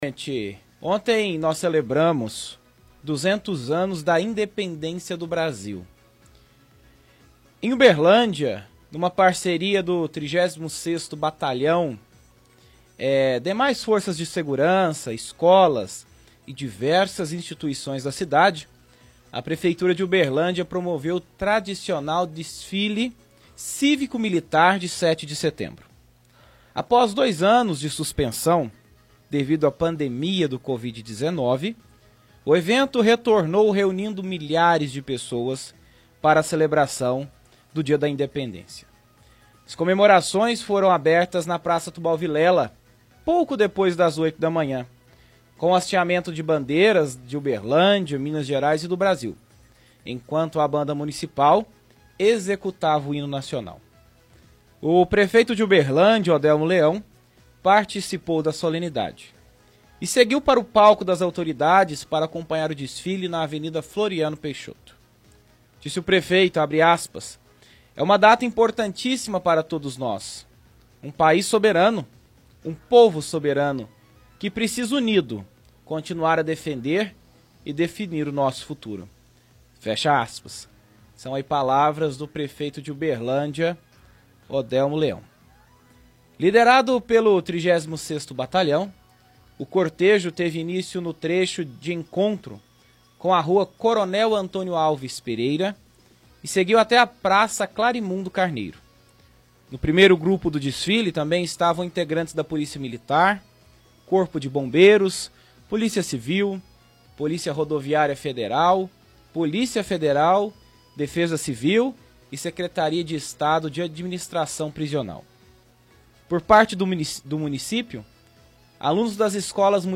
Leitura de release: Uberlândia celebra 200 anos da Independência do Brasil com desfile cívico-militar.